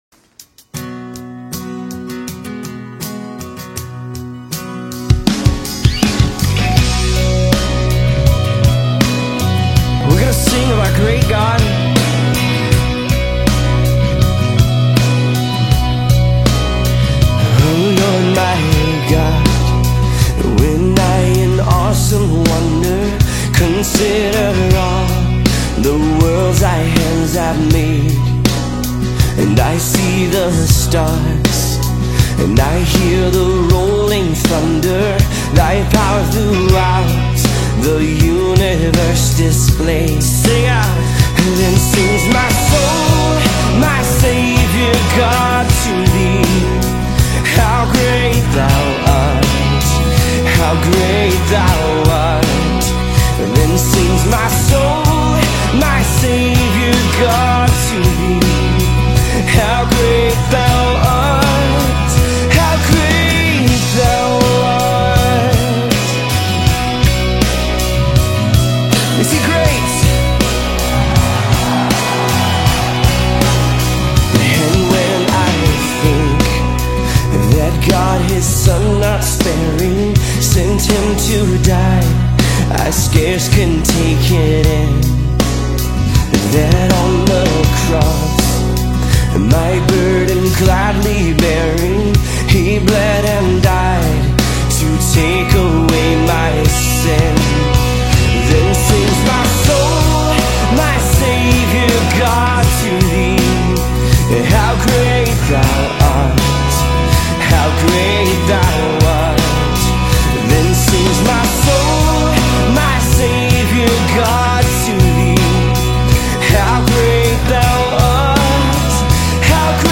Top Christian Hymns